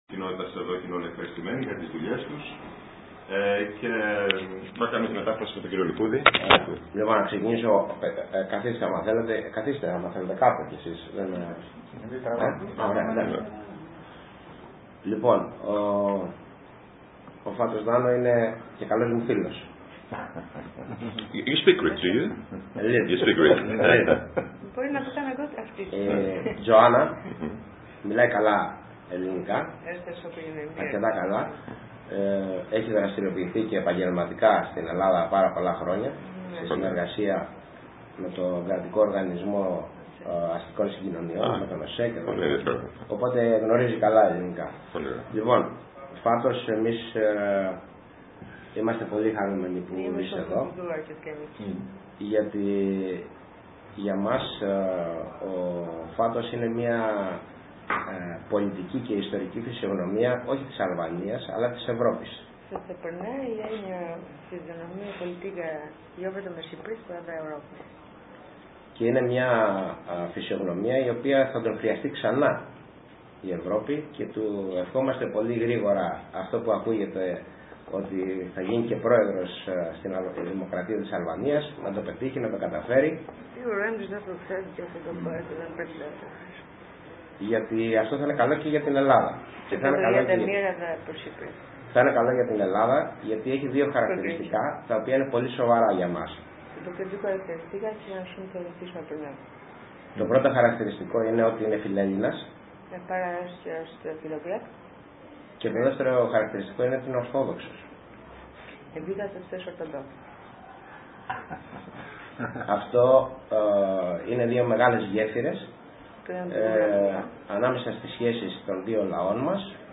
Την Πέμπτη 9 Ιουλίου 2015, το Δημαρχιακό Μέγαρο Κεφαλονιάς επισκέφθηκε, μετά από Πρόσκληση, ο τ.Πρωθυπουργός της Αλβανίας κ.Fatos Nano συνοδευόμενος από την σύζυγο του.
ΧΑΙΡΕΤΙΣΜΟΣ ΑΝΑΠΛΗΡΩΤΗ ΔΗΜΑΡΧΟΥ